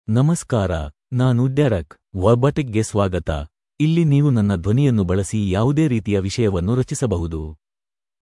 Derek — Male Kannada (India) AI Voice | TTS, Voice Cloning & Video | Verbatik AI
DerekMale Kannada AI voice
Derek is a male AI voice for Kannada (India).
Voice sample
Male